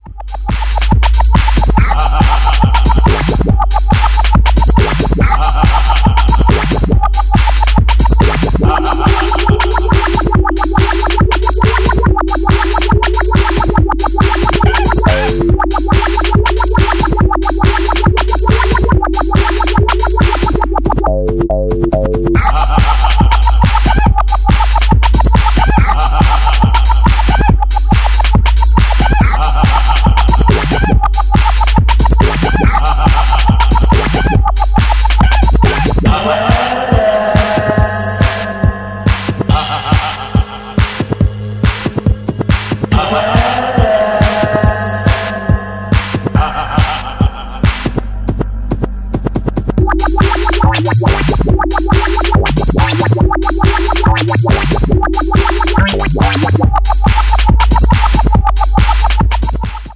141 bpm